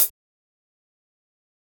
nightcore-hat.wav